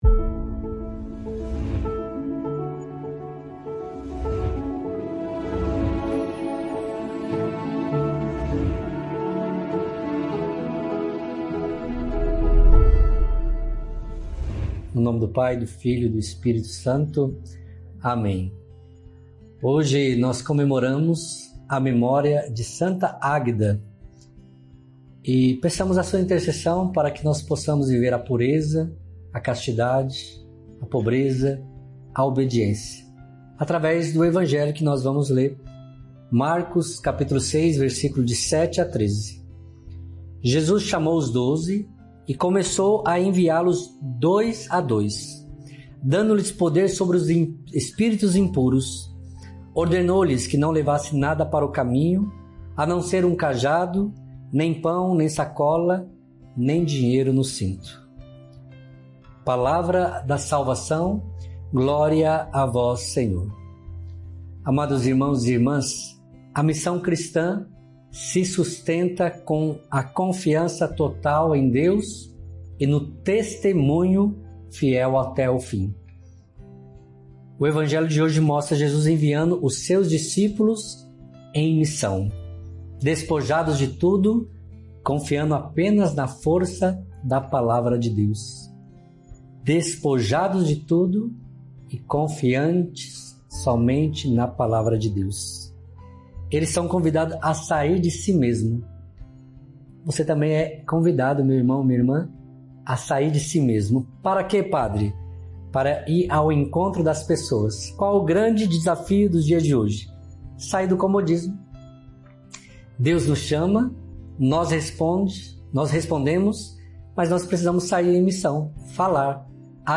Homilia Diária: Confiança em Deus e o exemplo de Santa Águeda